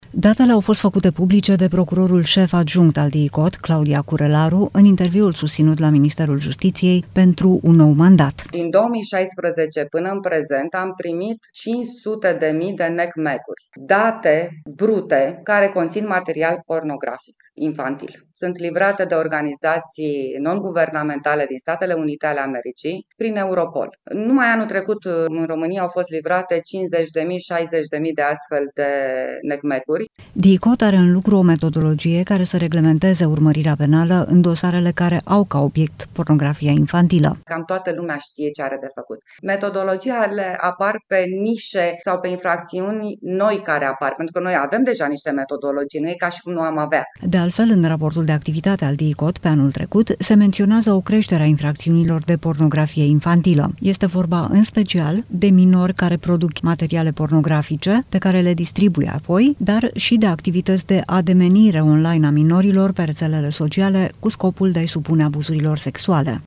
Datele au fost făcute publice de procurorul șef-adjunct al DIICOT, Claudia Curelaru, într-un interviu susținut la Ministerul Justiției pentru un nou mandat.